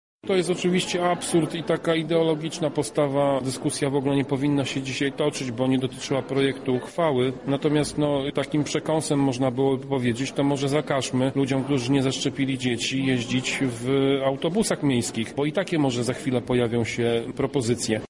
Podczas obrad padła również propozycja premiowania dzieci zaszczepionych. Sprzeciw wobec tego pomysłu zgłosił radny z Prawa i Sprawiedliwości, Tomasz Pitucha: